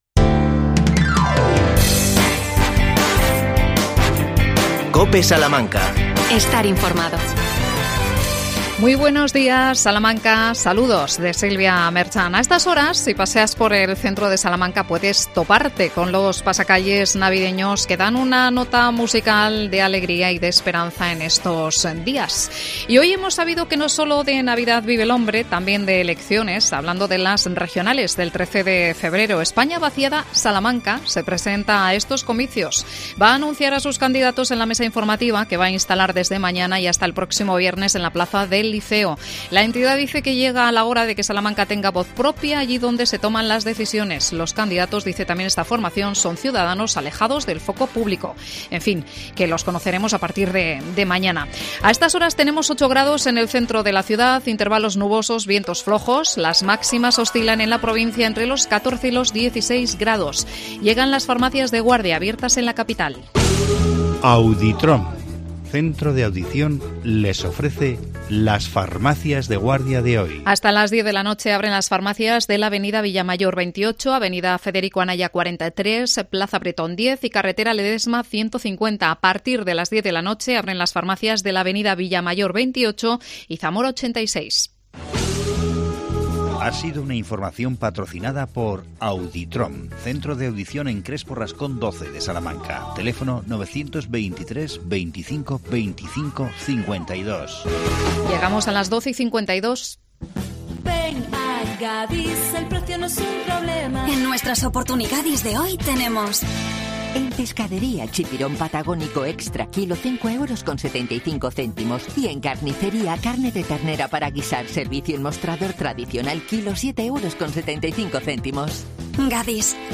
AUDIO: Entrevistamos al concejal popular Fernando Rodríguez. El tema: el Puerto Seco y la subvención de la Junta.